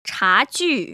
[chájù] 차쥐  ▶